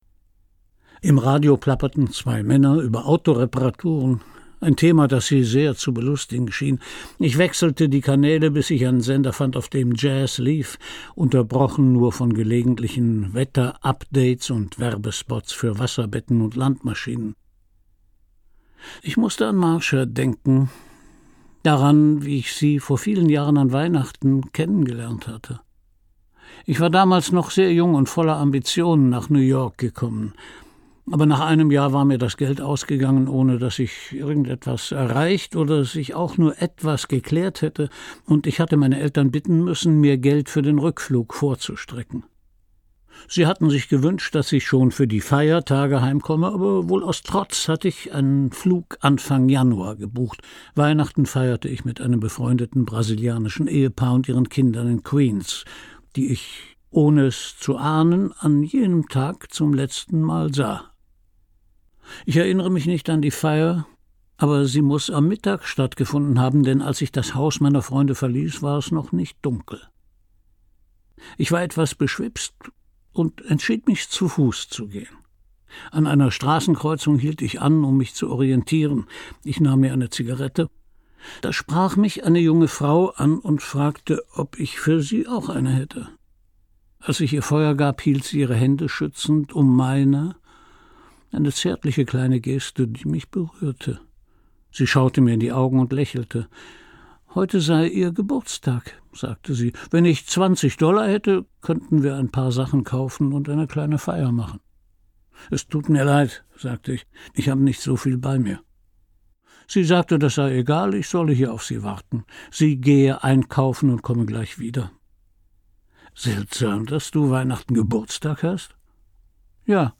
Christian Brückner (Sprecher)
Ungekürzte Lesung